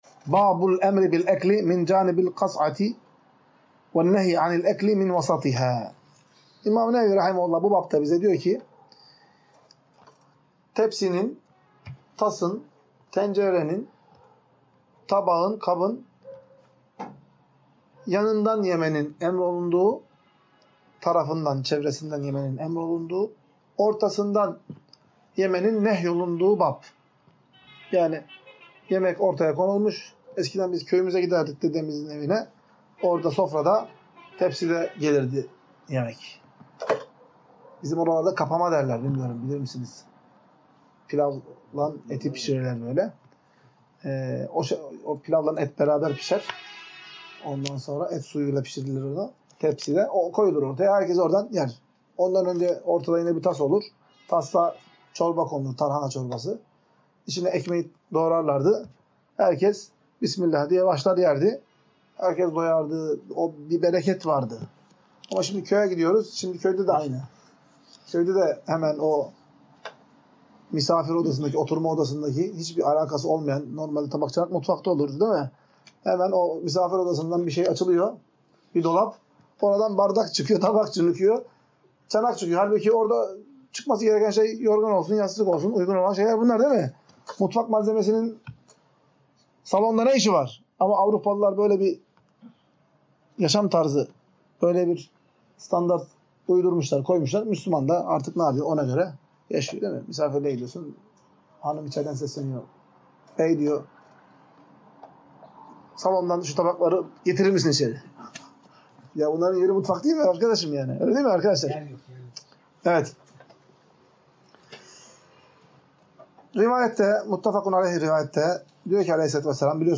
Ders - 8.